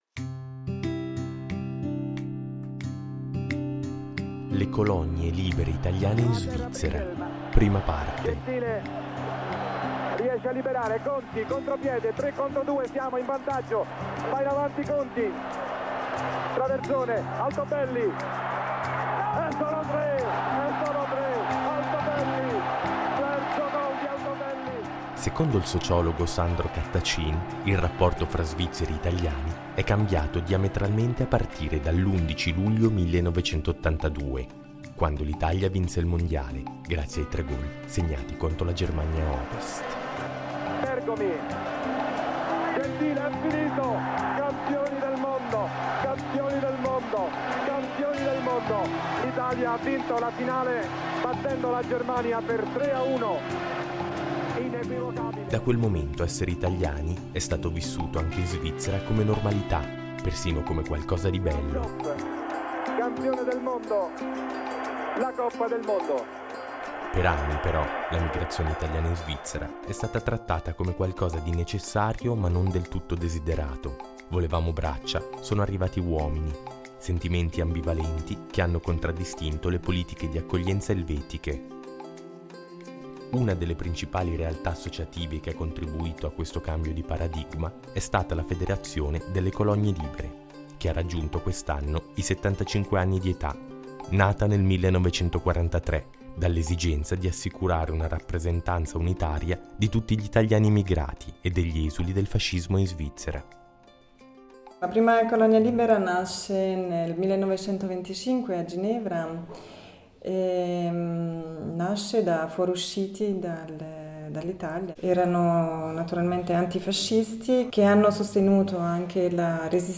Con questo audiodocumentario, cogliamo l’occasione del 75.mo anniversario di questa istituzione, per intraprendere un viaggio – in due tappe – sulle tracce della storia delle Colonie Libere, con uno sguardo sulle prospettive e sulle battaglie future.